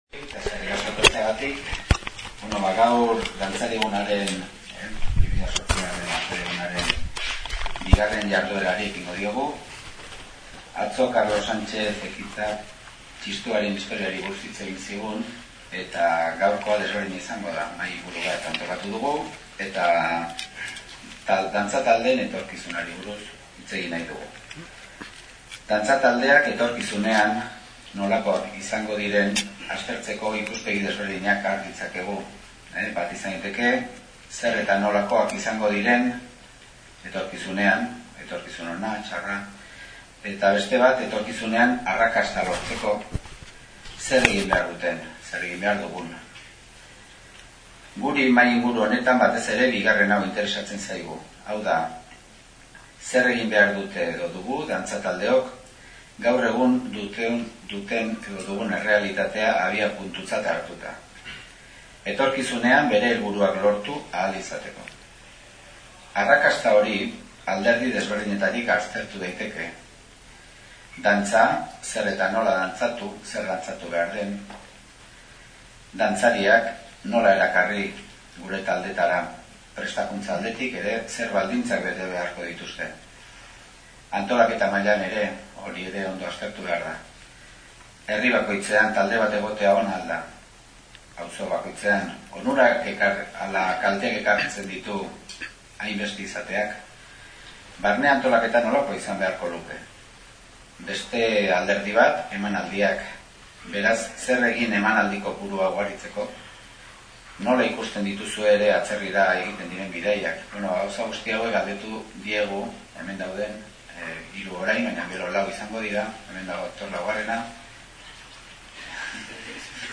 2008-09-25 Irun Dantza taldeak etorkizunean mahai ingurua